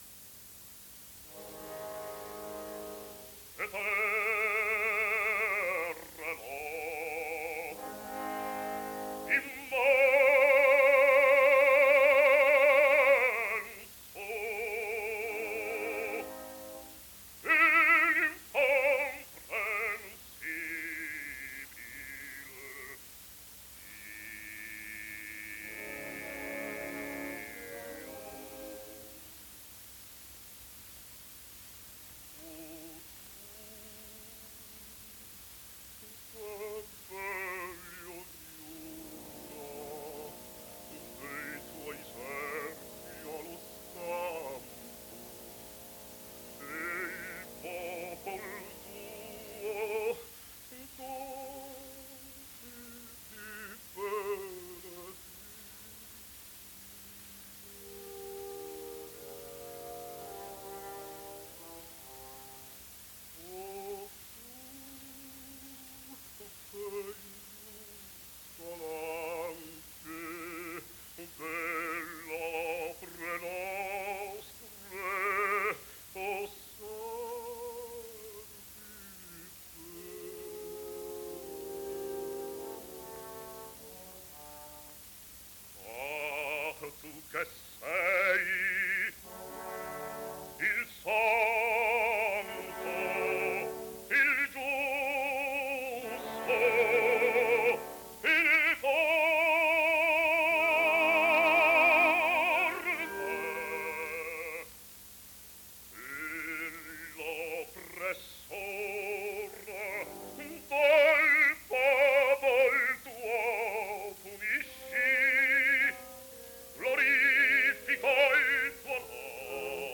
Basso NAZZARENO DE ANGELIS
De Angelis incise moltissimi dischi, sia acustici con la Fonotipia 1905 -1909, sia incisioni elettriche Columbia 1929-1937, di cui sotto ascolterete: